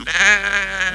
Schafsounds
Lamm:                      MäÄÄÄÄäääähhhhhhh  (wav 21 KB)